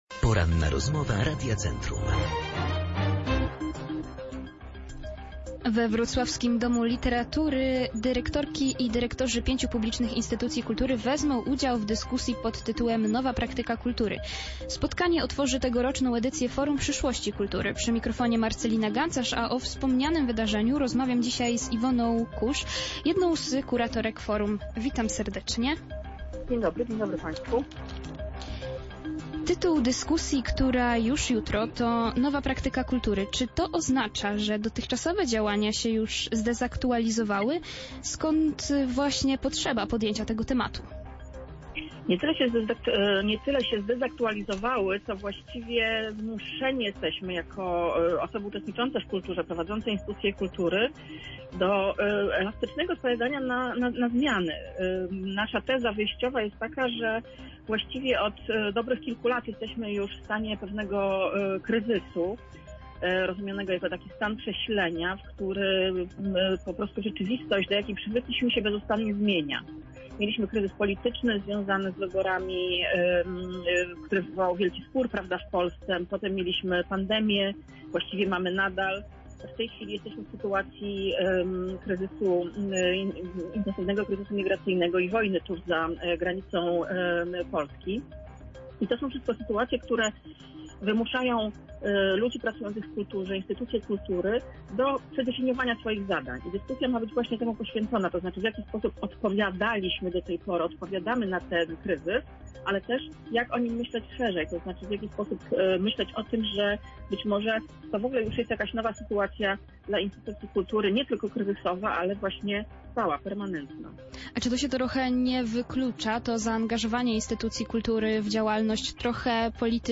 Ten temat poruszyliśmy podczas Porannej Rozmowy Radia Centrum.
Forum Przyszłości Kultury cała rozmowa